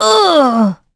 Hilda-Vox_Damage_03_b.wav